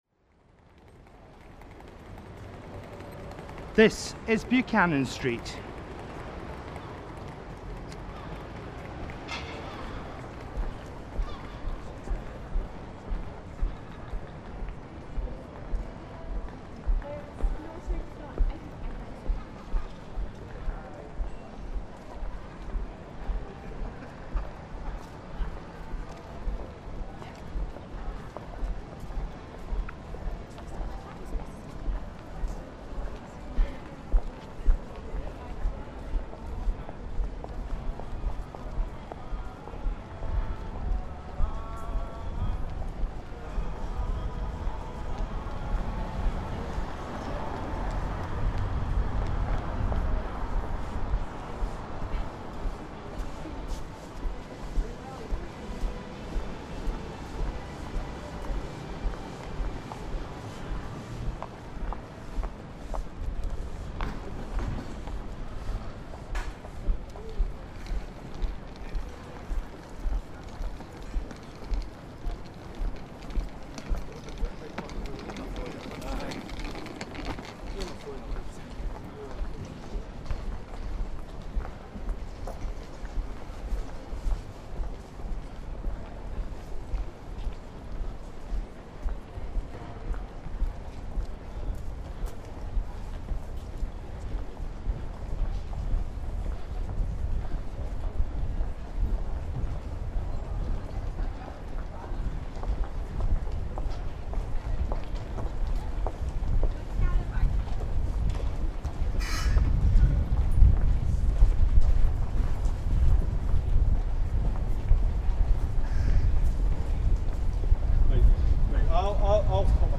Glasgow Royal Concert Hall to Argyle Street
buchanan-street.mp3